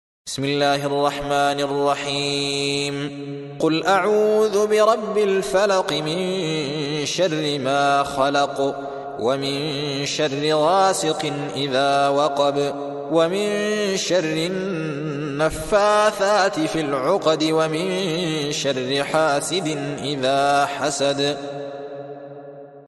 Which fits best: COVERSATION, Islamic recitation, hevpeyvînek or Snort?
Islamic recitation